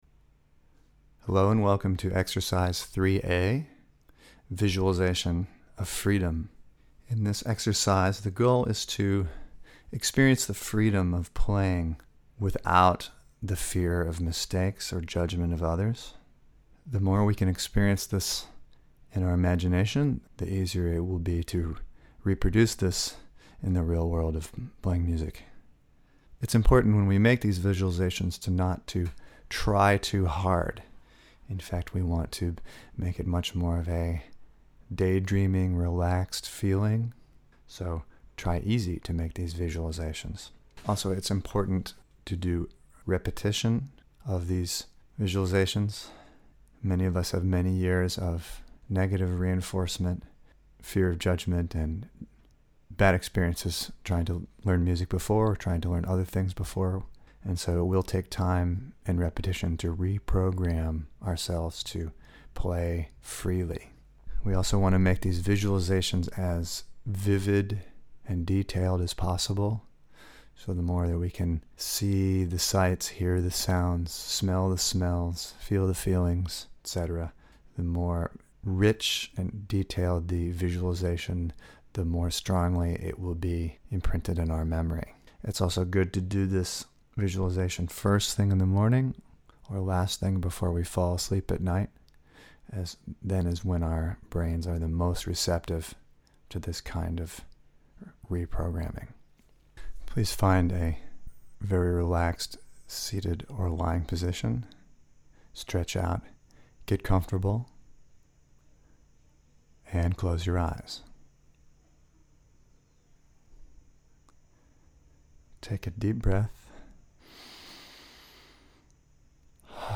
Exercise 3A: Visualization for musical freedom.